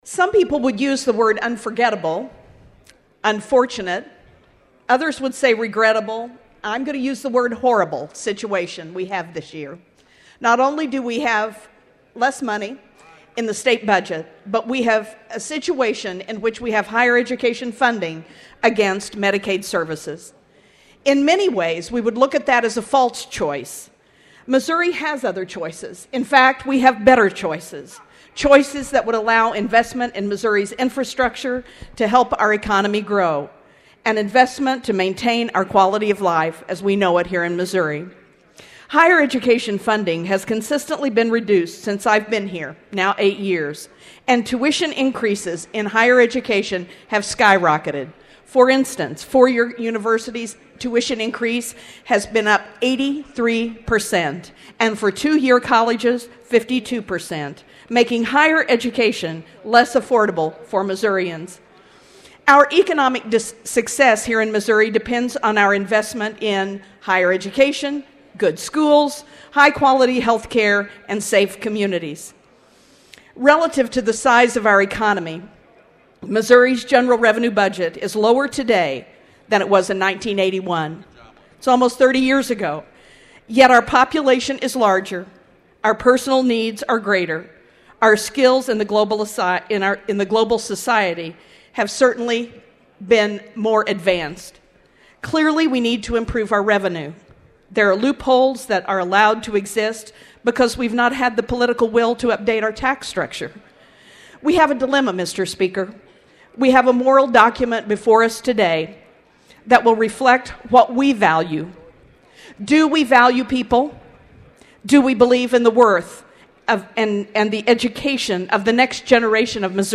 AUDIO:  Representative Sara Lampe’s statement opening budget debate on the House Floor, 2:43
Lampe-opening.mp3